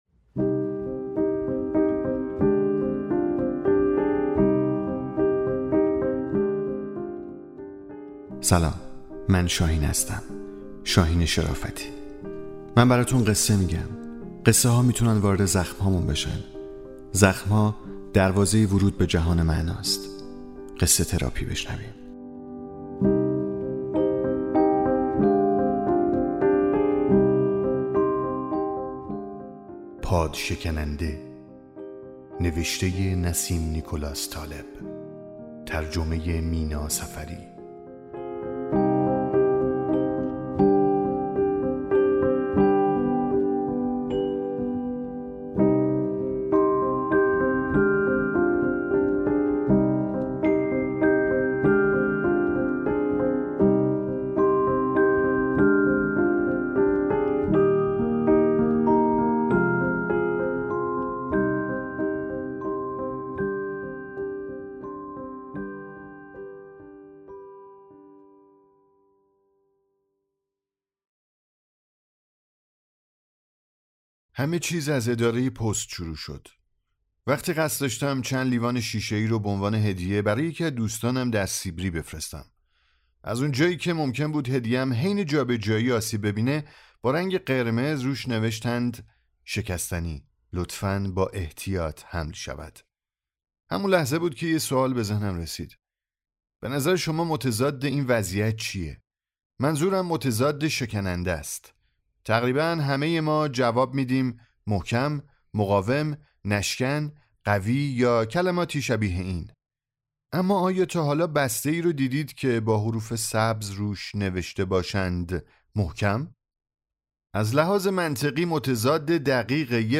ساز و آواز – آواز افشاری